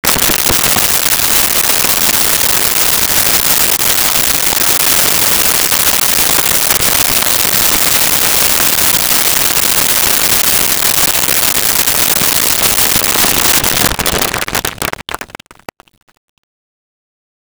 Nice Presentation Applause
Nice Presentation Applause.wav